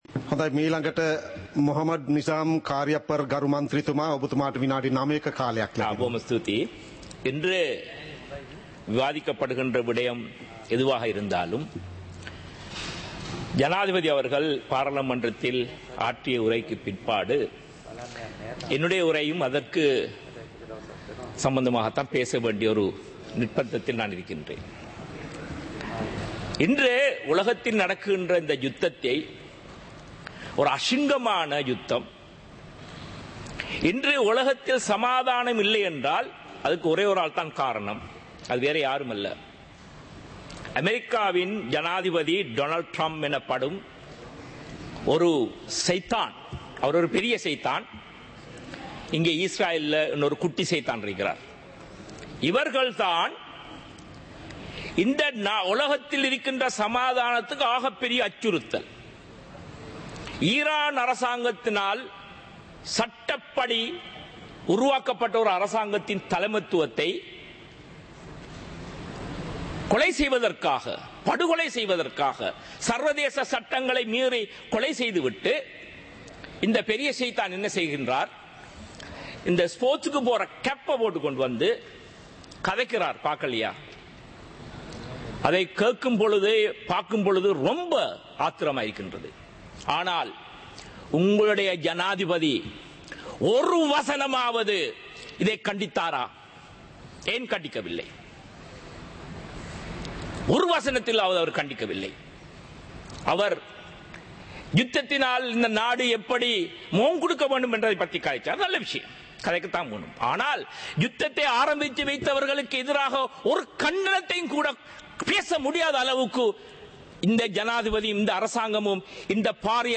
இலங்கை பாராளுமன்றம் - சபை நடவடிக்கைமுறை (2026-03-03)